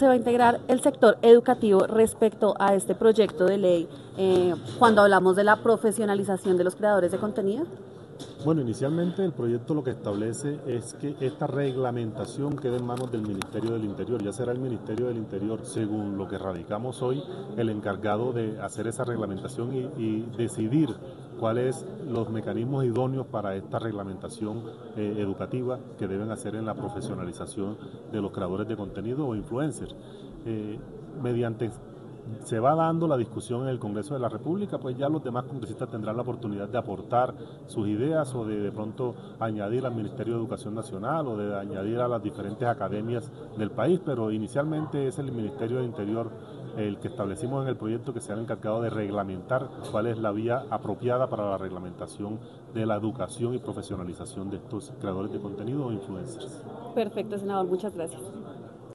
El senador Julio Elias Vidal, quien pertenece al partido de la U, se refirió la profesionalización de los creadores de contenido:
AUDIO-SENADOR-VIDAL.mp3